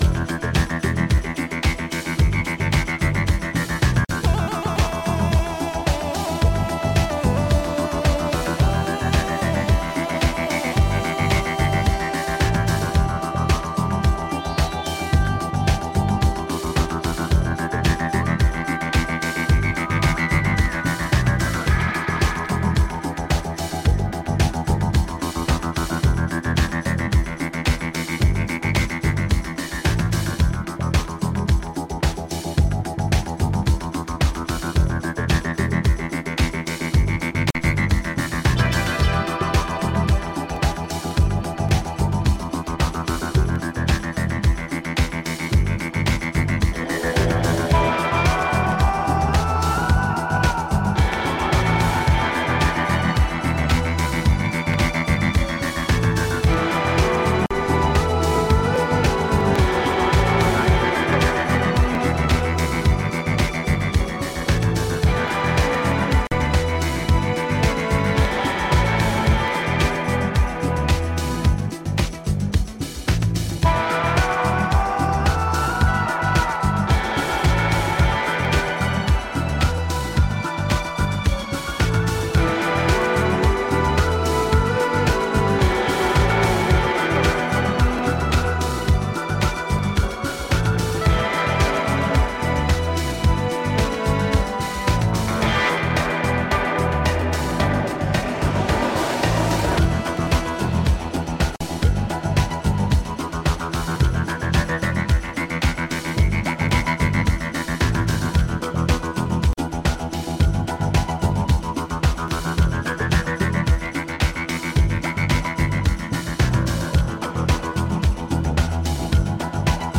deep dubby and sometimes chuggy disco!